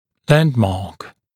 [‘lændmɑːk][‘лэндма:к]ориентир, цефалометрическая точка